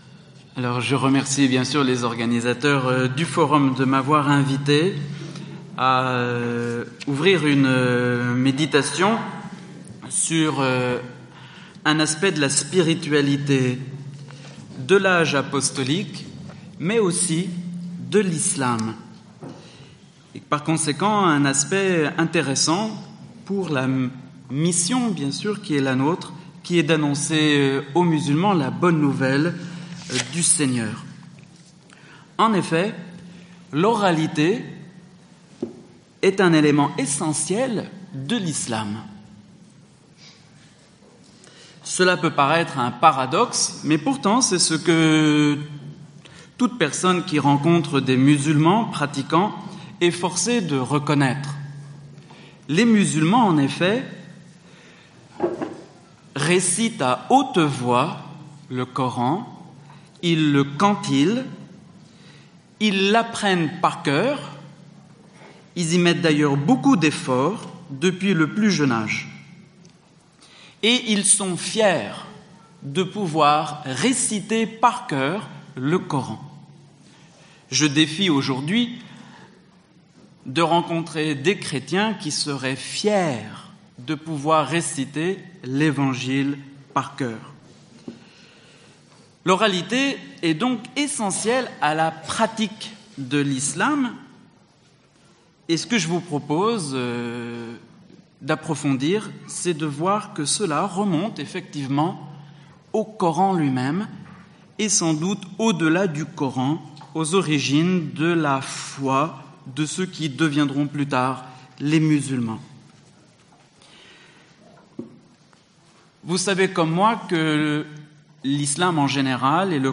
Intervention lors du Forum du 26-27 mai 2018 à Paris.